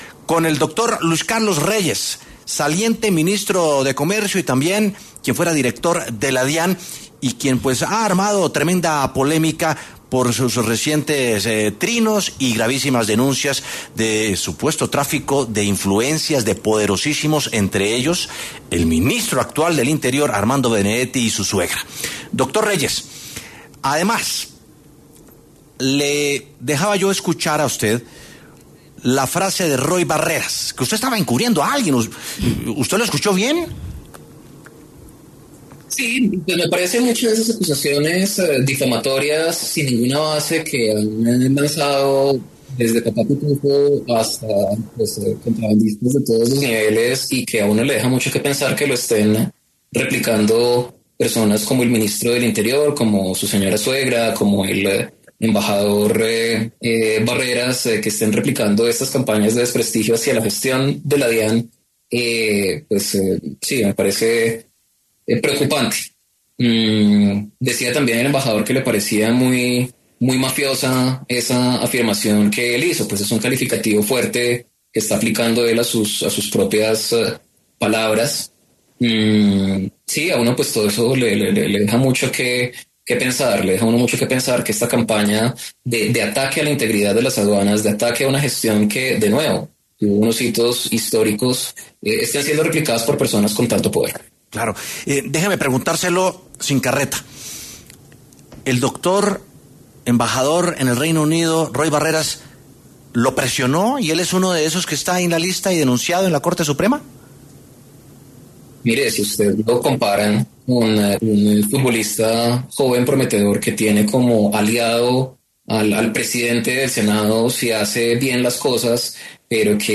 El exministro de Comercio Luis Carlos Reyes pasó por los micrófonos de W Sin Carreta para hablar sobre su salida del Gobierno Petro, pero también sobre las declaraciones de Roy Barreras, quien aseguró que el también exdirector de la DIAN estaba encubriendo a alguien.